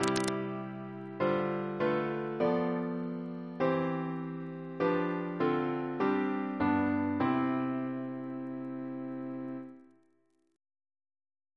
Single chant in C Composer: Barry W. C. Ferguson (b.1942) Reference psalters: CWP: 151